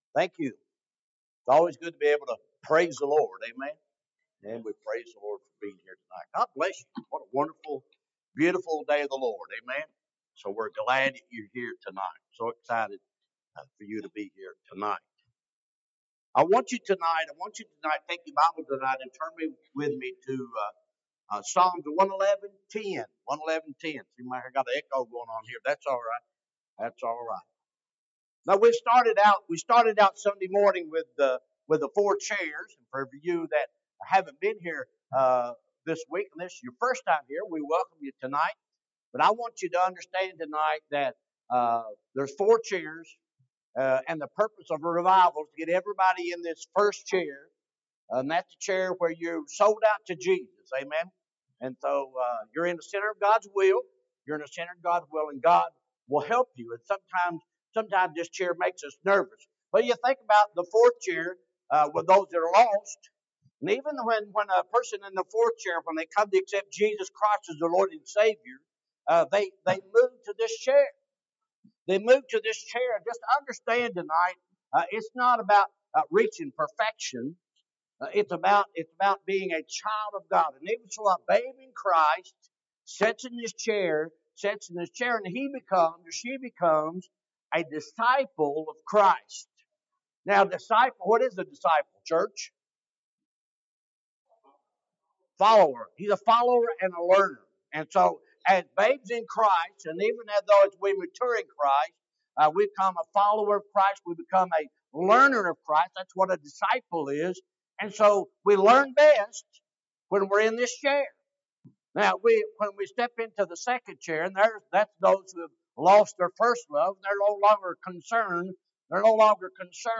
Monday Evening Revival Service